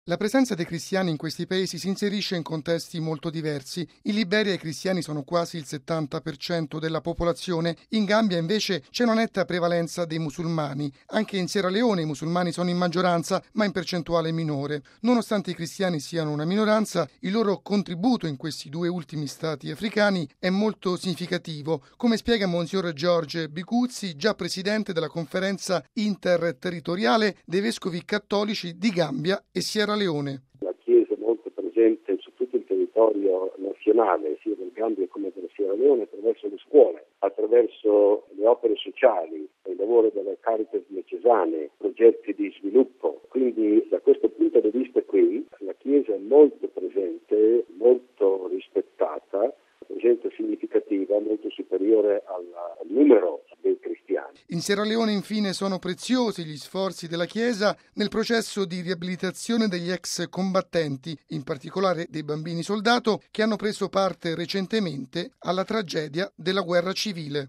◊   Benedetto XVI ha ricevuto stamani alcuni vescovi di Gambia, Liberia e Sierra Leone, in Vaticano per la visita "ad Limina": tra i presuli che hanno incontrato il Papa c'era anche il vescovo di Makeni, mons. George Biguzzi, che ai nostri microfoni illustra la situazione della Chiesa in questi Paesi dell'Africa Occidentale.